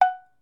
Cowbell_OS_1
Bell Cartoon Cow Cowbell Ding Dong H4n Ring sound effect free sound royalty free Movies & TV